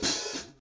Drum Samples
H i H a t s